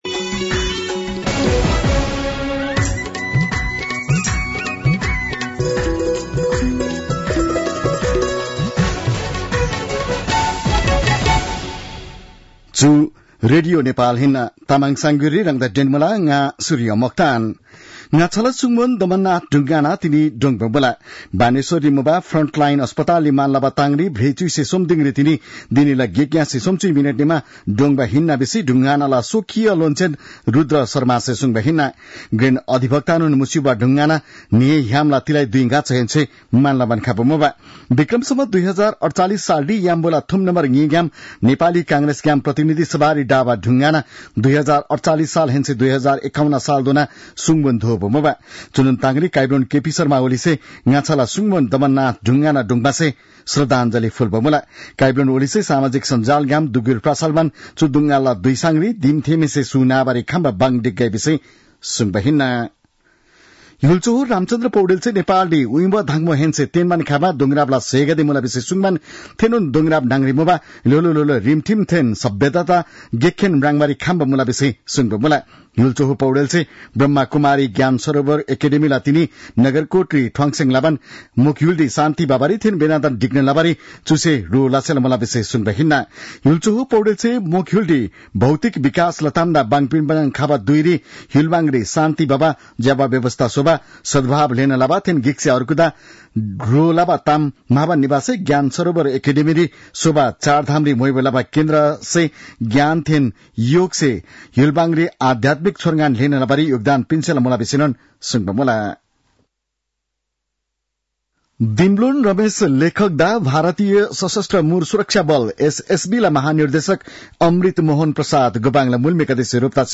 तामाङ भाषाको समाचार : ३ मंसिर , २०८१